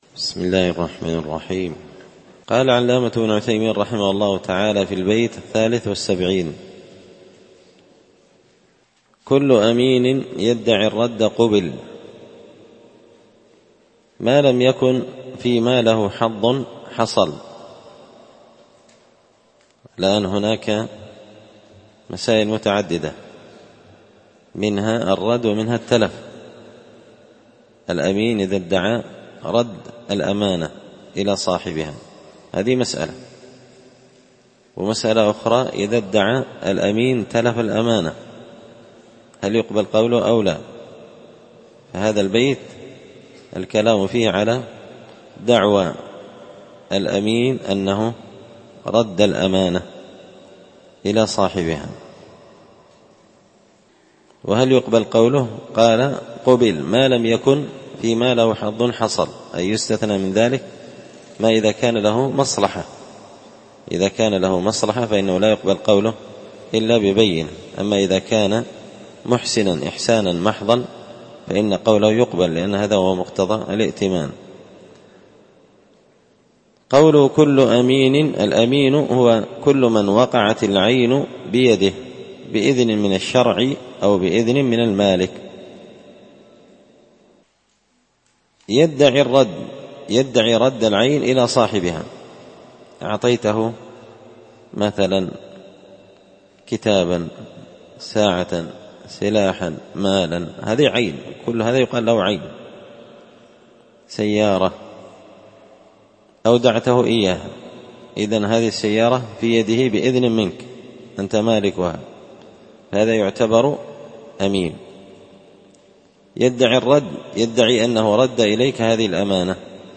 تسهيل الوصول إلى فهم منظومة القواعد والأصول ـ الدرس 41
مسجد الفرقان